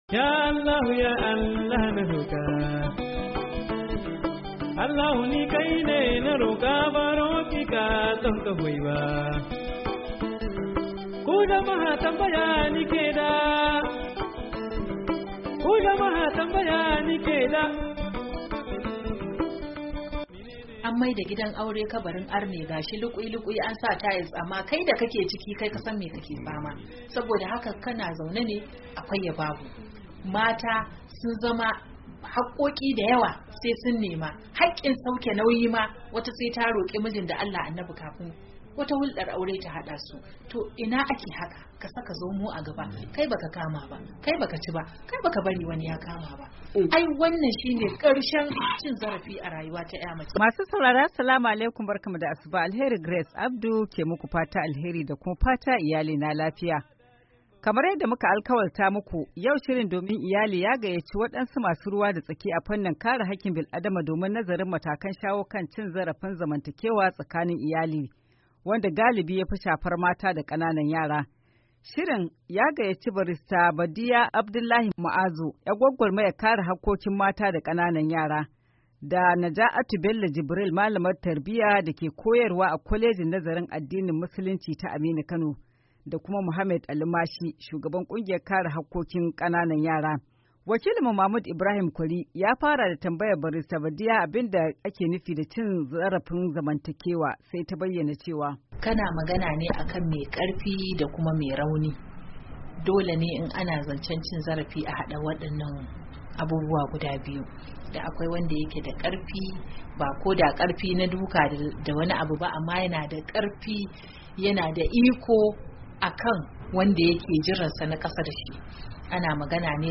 Tattaunawa Kan Cin Zarafin Iyali A Gida Kashi Na Daya